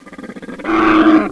wolt_die2.wav